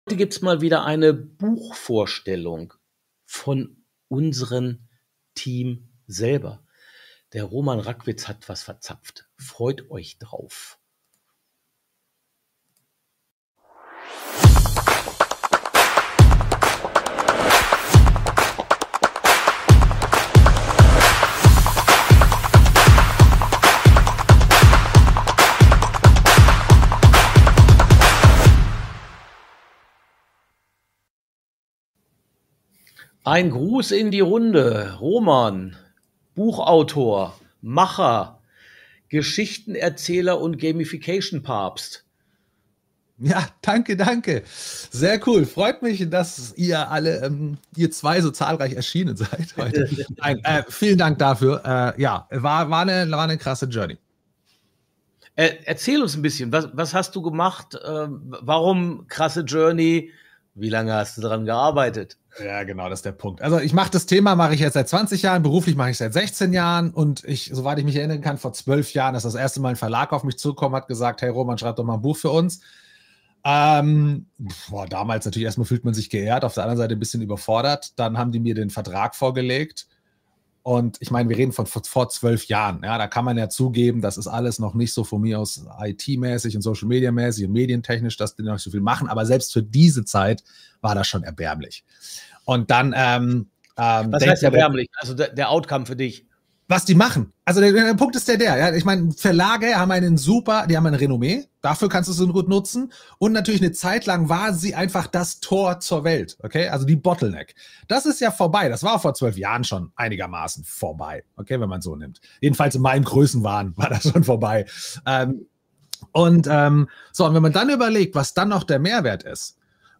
Freu Dich auf druckfrische Insights - wie immer im LiveStream am Mittwoch, pünktlich um 12:29 Uhr.